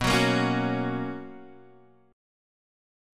BM7sus2 chord